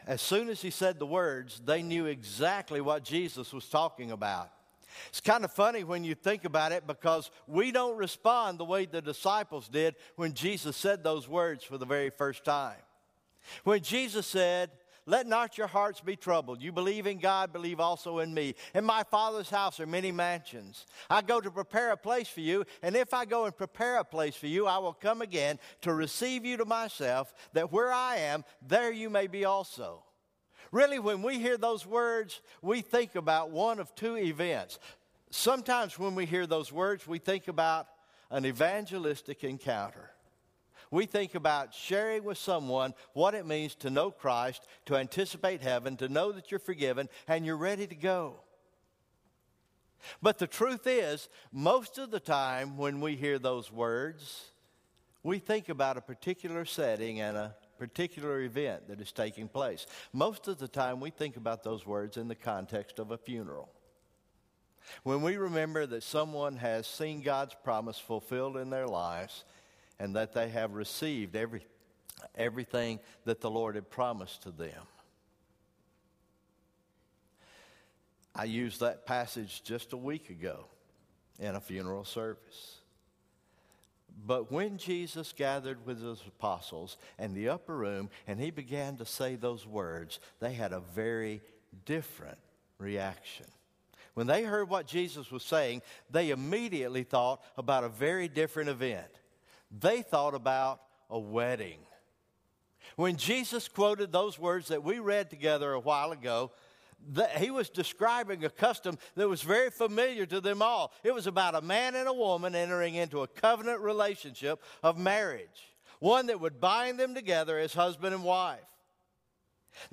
May 21, 2017 Morning Worship Service